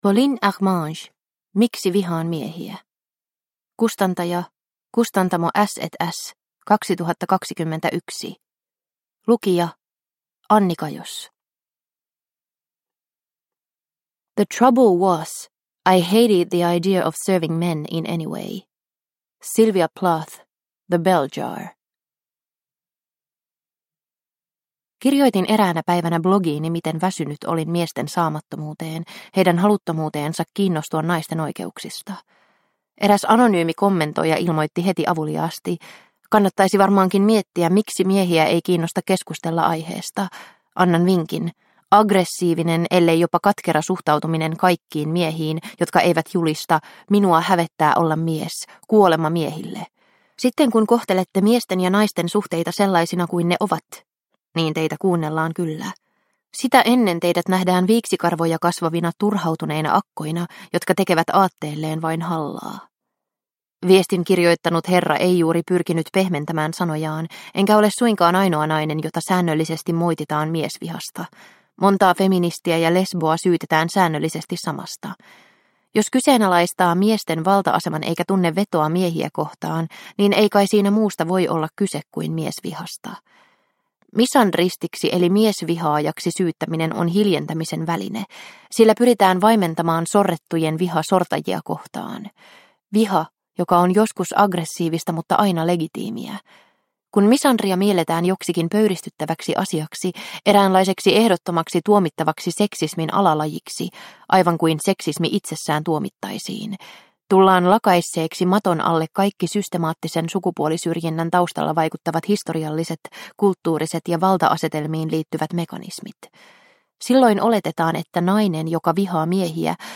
Miksi vihaan miehiä – Ljudbok – Laddas ner